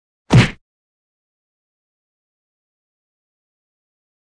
赤手空拳击中肉体9-YS070524.wav
通用动作/01人物/03武术动作类/空拳打斗/赤手空拳击中肉体9-YS070524.wav
• 声道 立體聲 (2ch)